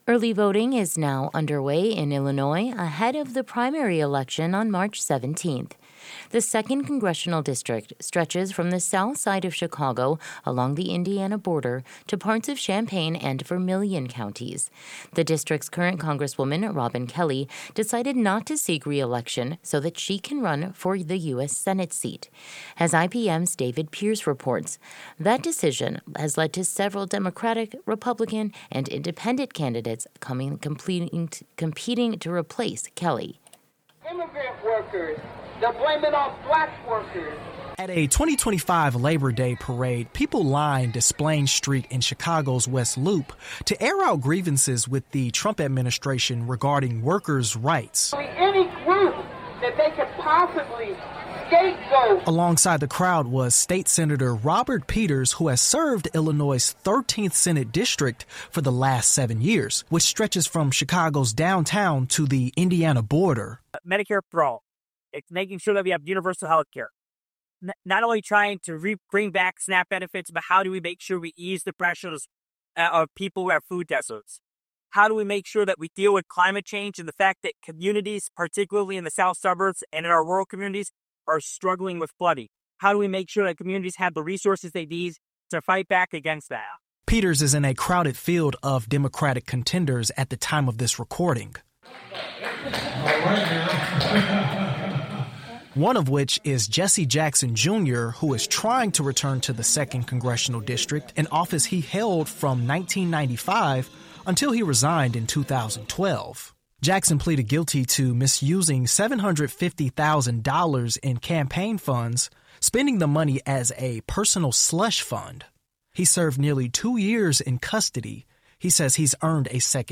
IPM News State Sen. Robert Peters (D) 2nd congressional district candidate.